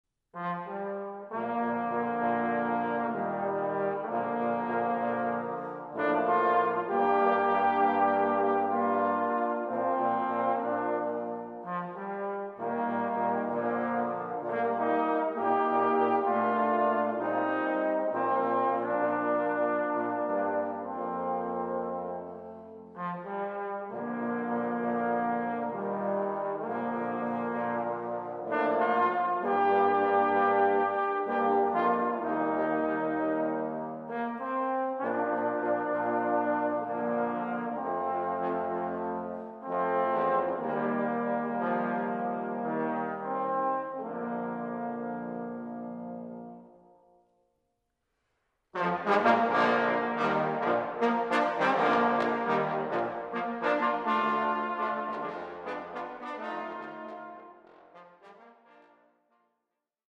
Besetzung: Instrumentalnoten für Posaune